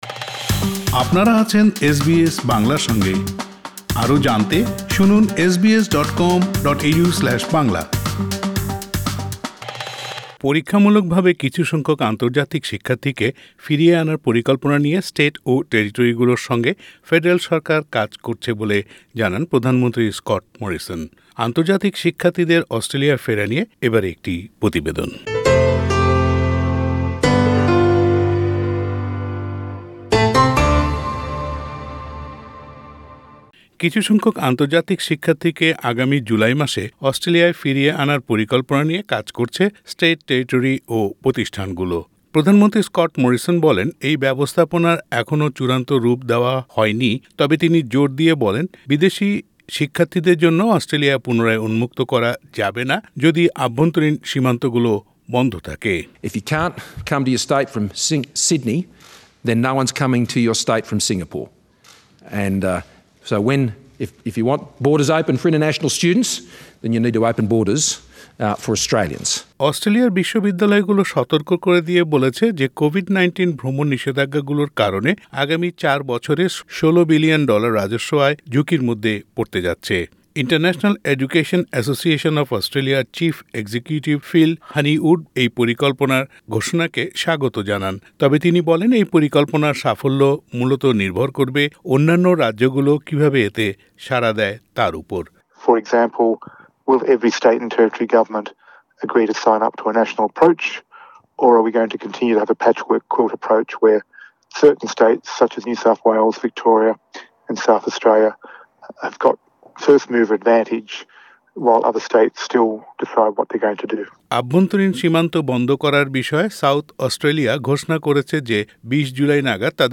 পরীক্ষামূলকভাবে কিছু সংখ্যক আন্তর্জাতিক শিক্ষার্থীকে ফিরিয়ে আনার পরিকল্পনা নিয়ে স্টেট ও টেরিটোরিগুলোর সঙ্গে ফেডারাল সরকার কাজ করছে বলে জানান প্রধানমন্ত্রী স্কট মরিসন।আন্তর্জাতিক শিক্ষার্থীদের অস্ট্রেলিয়ায় ফেরা নিয়ে প্রতিবেদনটি শুনতে উপরের অডিও প্লেয়ারের লিংকটিতে ক্লিক করুন।